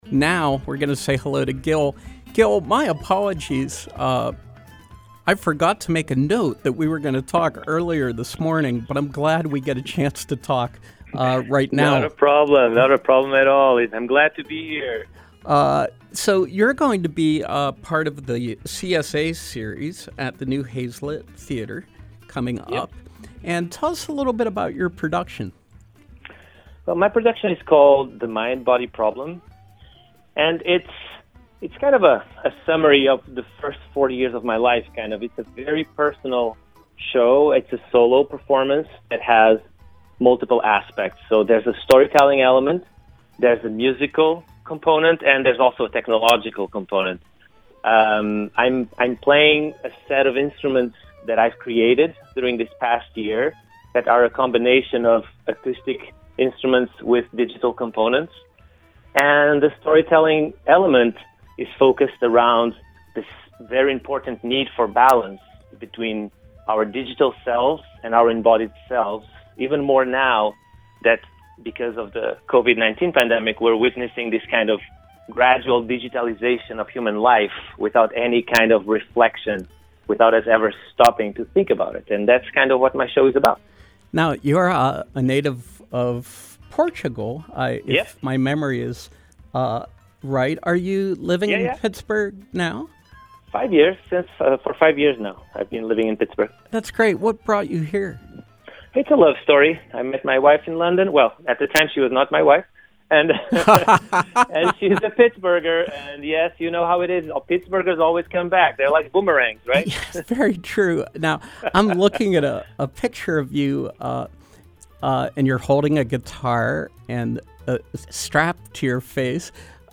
Interview: CSA Performance Series, The Mind-Body Problem